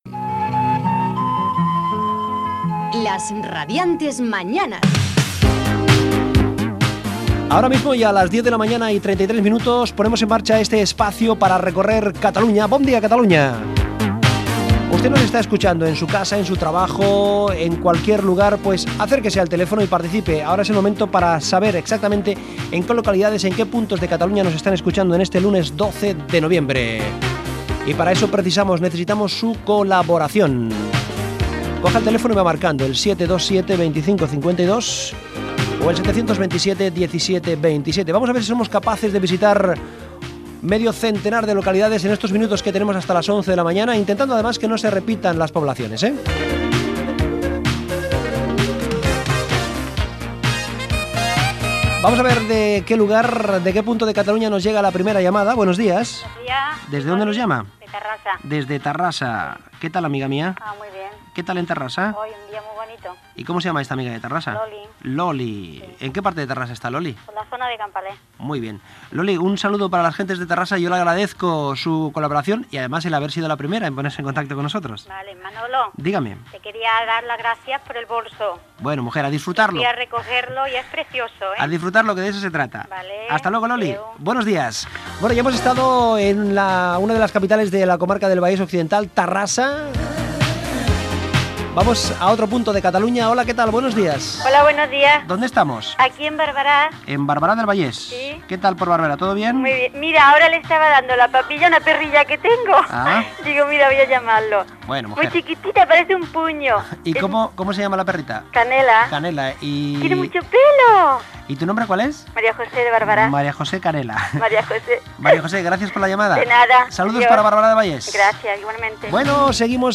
Indicatiu del programa, hora, presentació, telèfons i trucades telefòniques per saber des de quines localitats escolten el programa els oients .
Entreteniment